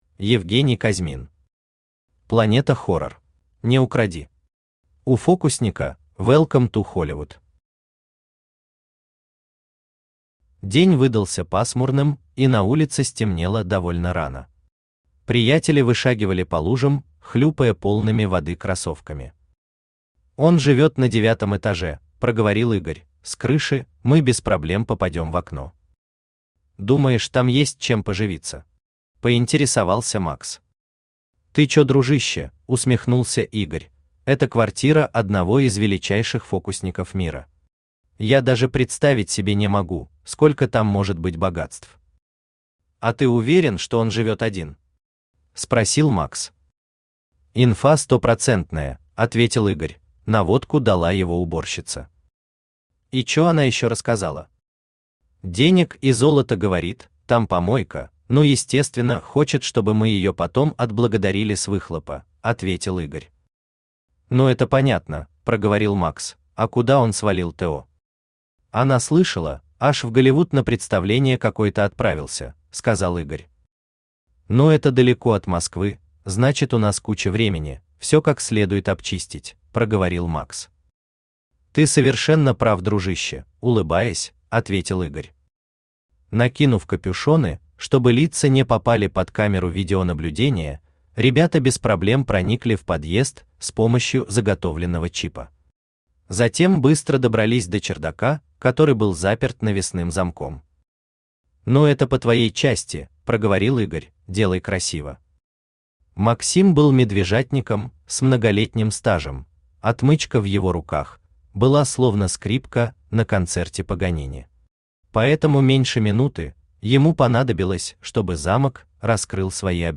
Аудиокнига Планета Хоррор | Библиотека аудиокниг
Aудиокнига Планета Хоррор Автор Евгений Михайлович Казьмин Читает аудиокнигу Авточтец ЛитРес.